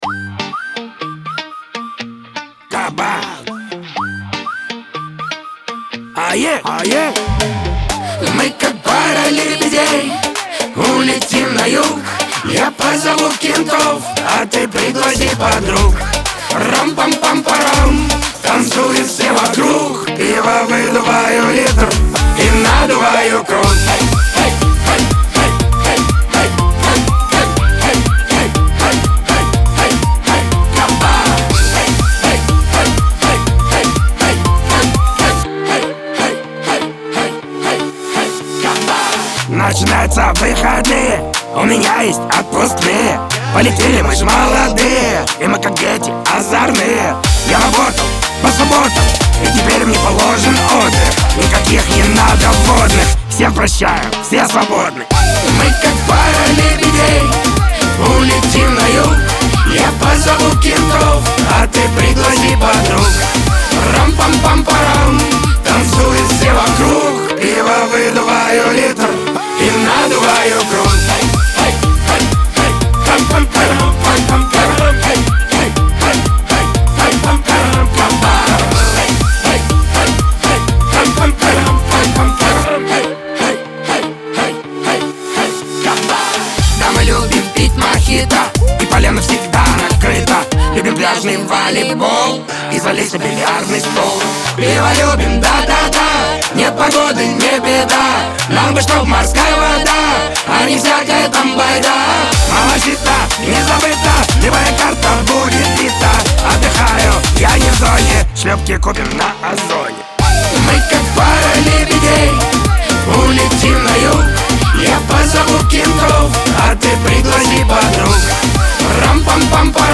Веселая музыка
Шансон